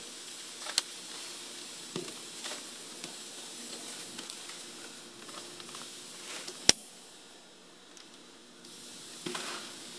Звук бумаги
Читает газету: